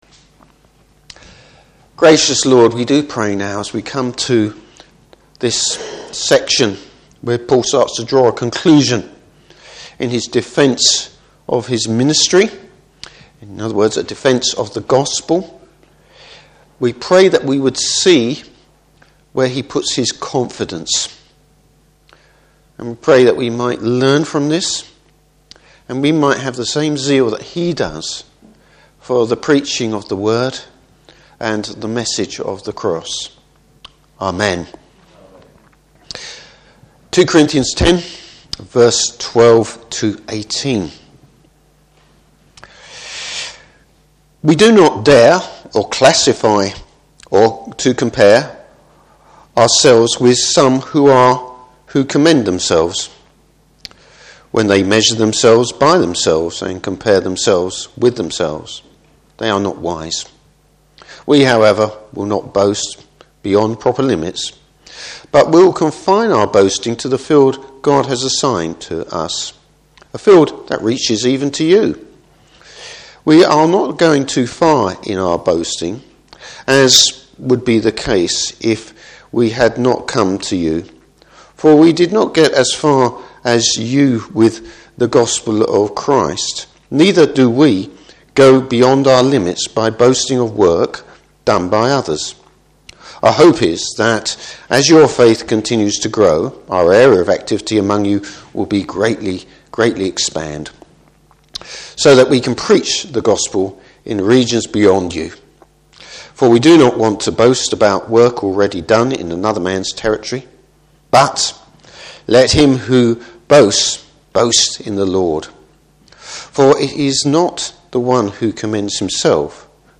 Service Type: Morning Service Paul is securer in what the Lord has called him to do.